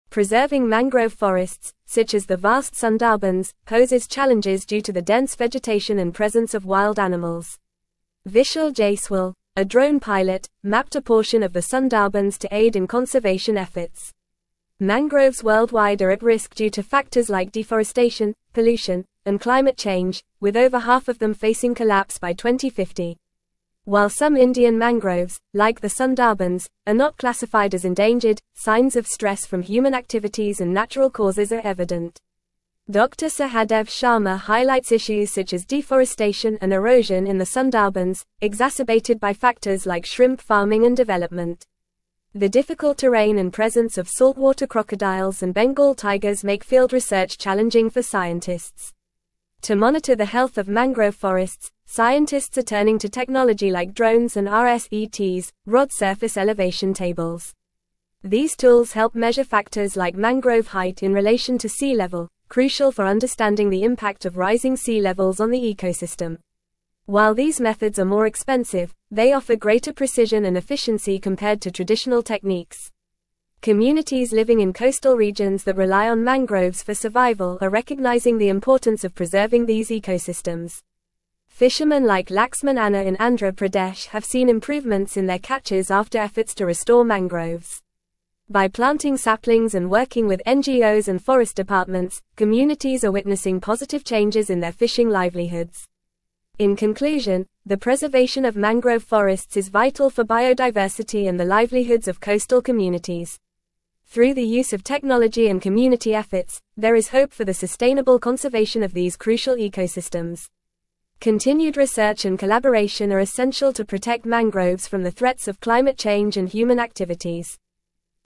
Fast
English-Newsroom-Advanced-FAST-Reading-Mapping-Sundarbans-Drones-Preserve-Worlds-Largest-Mangrove-Forest.mp3